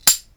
click2.wav